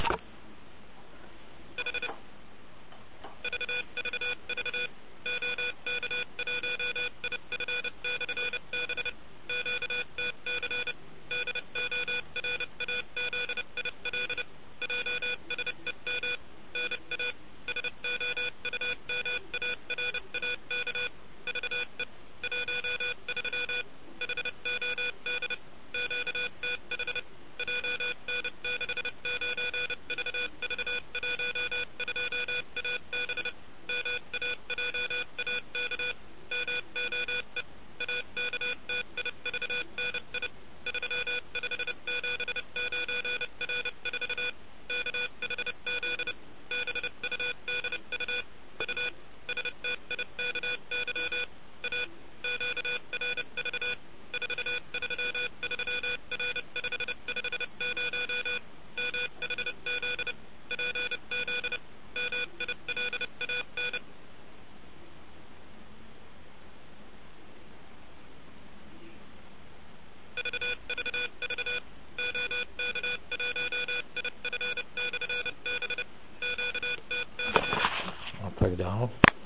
Samozřejmě nevím, kdy posluchač si ji poslechne a tak může být vysílána s různou čekací dobou libovolně dlouho.
Radiová SMS.mp3